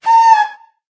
affectionate_scream.ogg